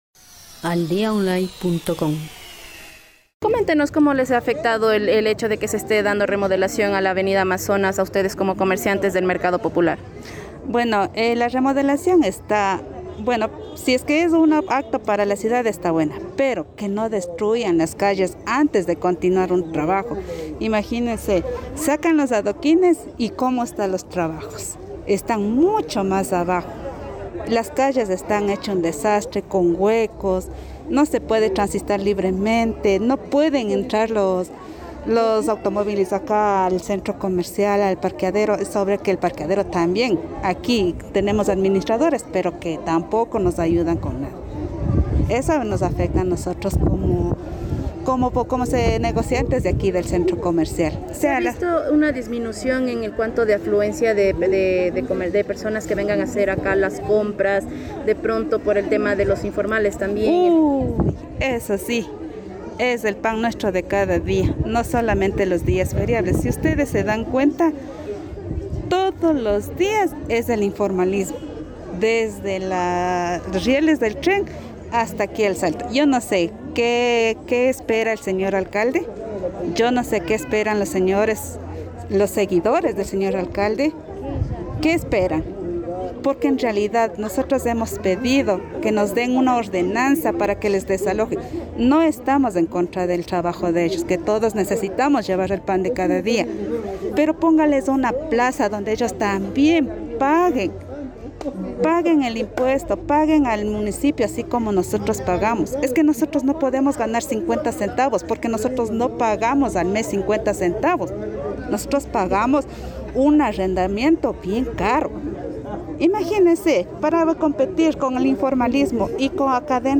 Por otra parte, una comerciante del Centro Comercial Popular, que por motivos personales evito dar su nombre, asegura que no ha evidenciado un apoyo por parte de las autoridades, ya que ha solicitado por varias ocasiones se de solución a este inconveniente, incluso asegura que varias veces ha dialogado con el director de Servicios Públicos para que se trate de manera urgente la ordenanza que regule el comercio en el este lugar.
comerciante_mezcla.mp3